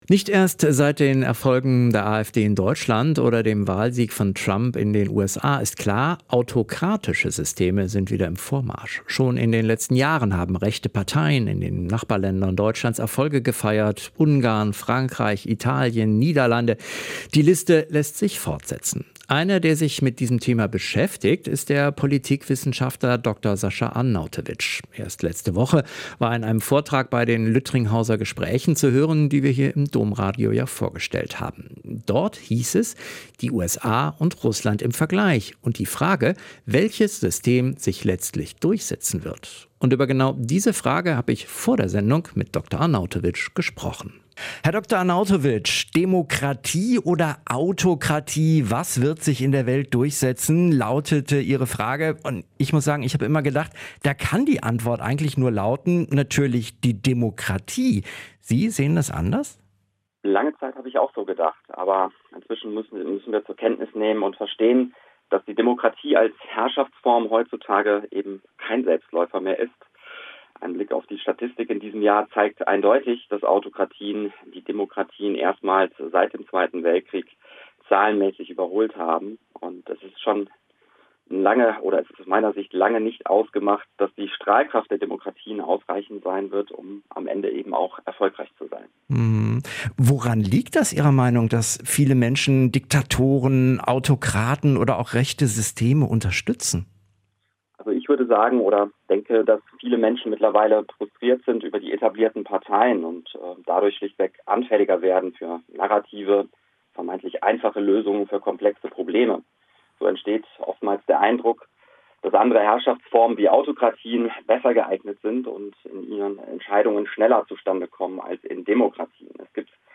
• Interview „Demokratie oder Autokratie